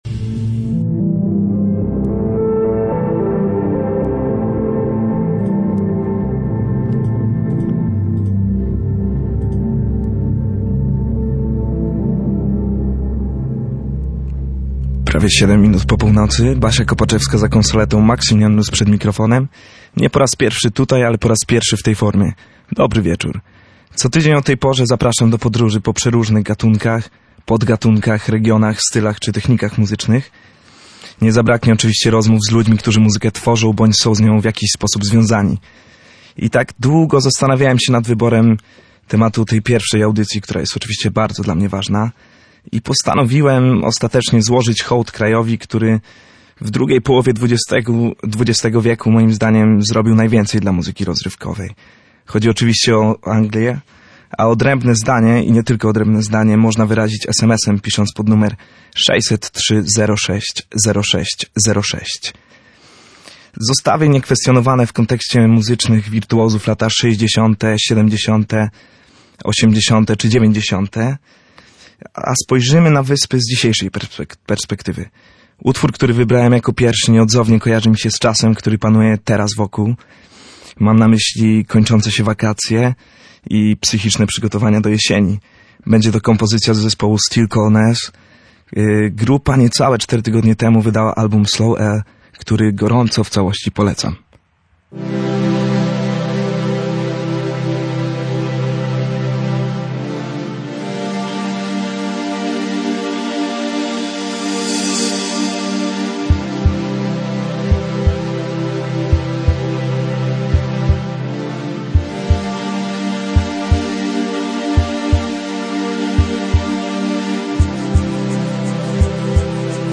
Pierwsza odsłona drugiej części audycji Stacja Północ była poświęcona najciekawszym brytyjskim wykonawcom. Od delikatnych, dream popowych brzmień, przez subtelną elektronikę, po instrumentalny współczesny jazz i indie rockowe brzmienia.